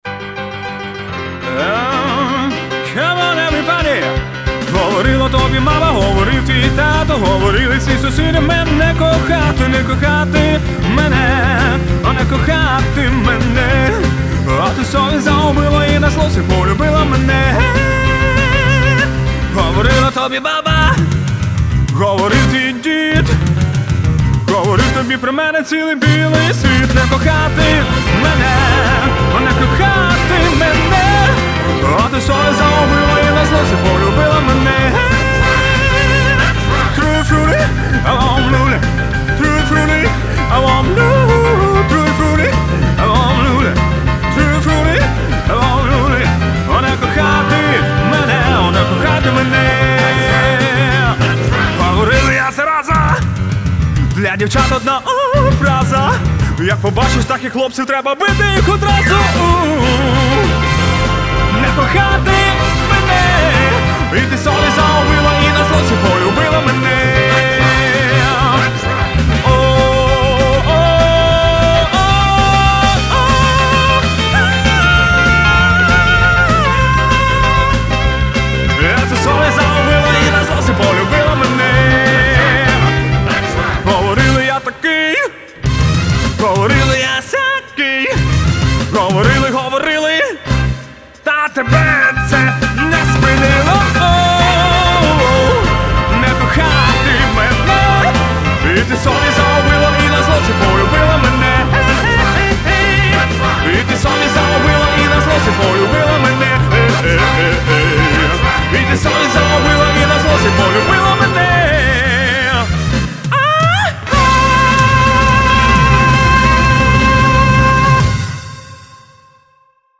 Rock&Roll